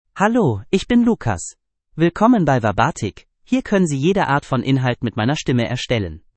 MaleGerman (Germany)
LucasMale German AI voice
Voice sample
Male
German (Germany)